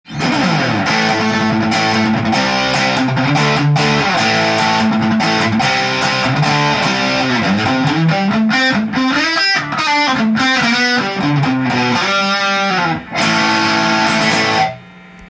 歪みをマックスで弾いてみた
歪を最強にして弾いてみましたが、原音がキチンと聞こえる感じで良いです！
歪系のデメリットでしたが、ちゃんと音程が聞こえます♪
boss.destotion2.m4a